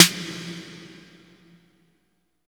34 808V.SN-L.wav